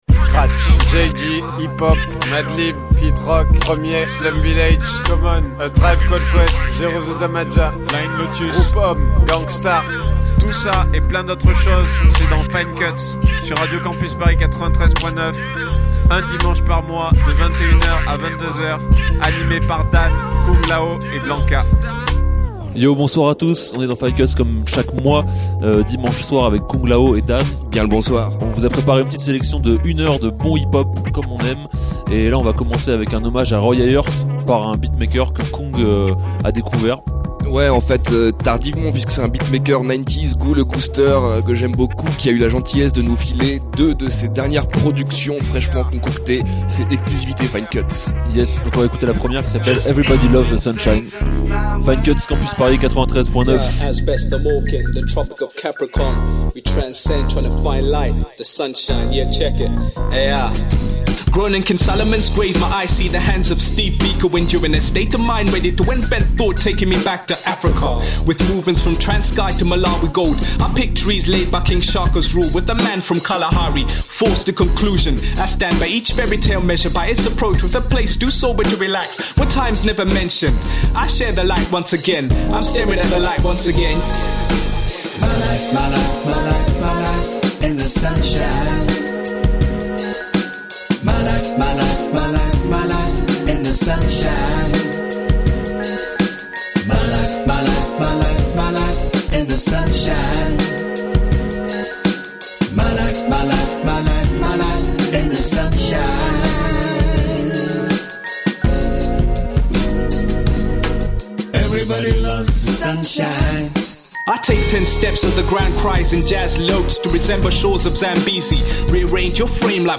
Une heure de mix pour apaiser les corps et les âmes
Hip-hop